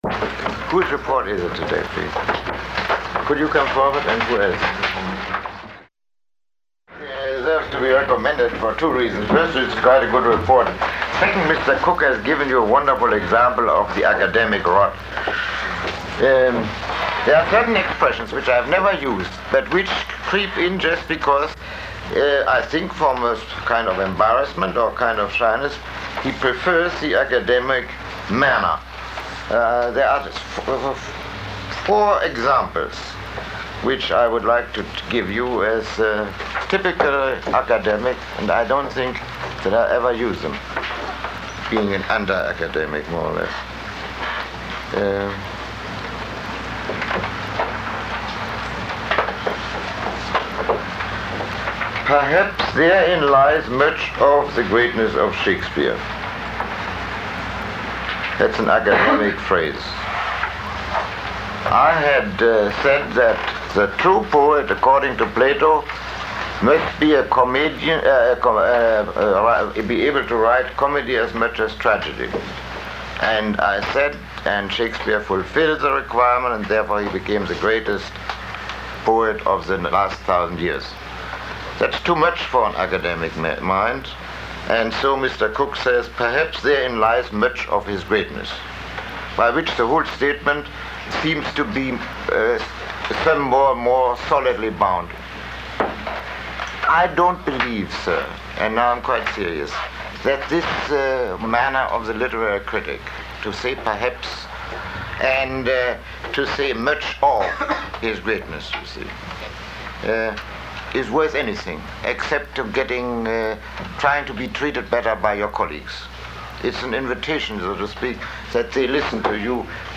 Lecture 05